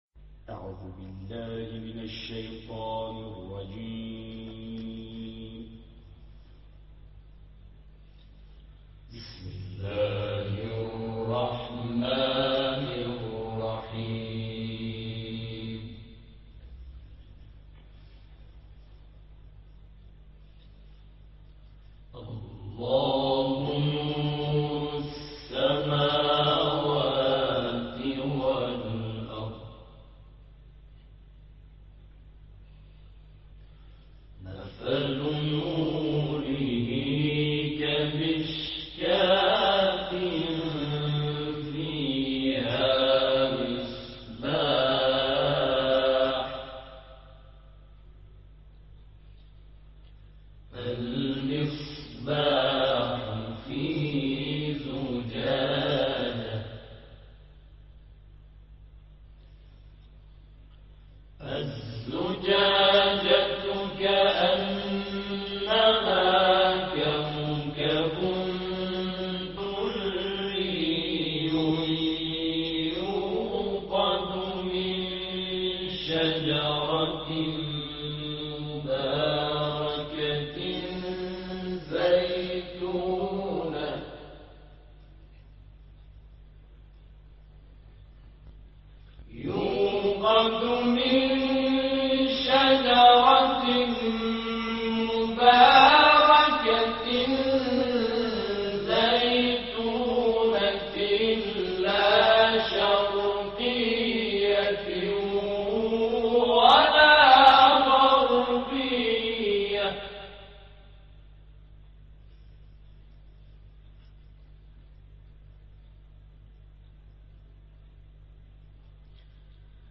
همخوانی سوره‌های نور و نصر از گروه سیرت‌النبی(ص)
گروه شبکه اجتماعی: هم‌خوانی زیبای گروه تواشیح سیرت النبی(ص) مشهد از سوره‌های مبارکه نور و نصر را می‌شنوید.